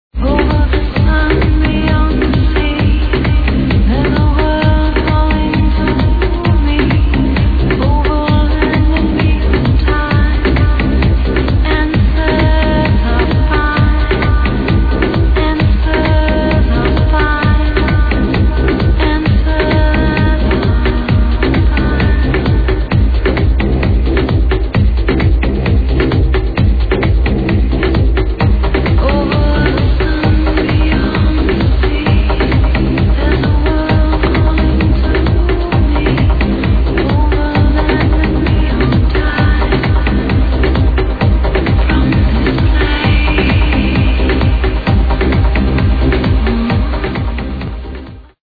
great vocals, would be good if it was id'd, sounds goood!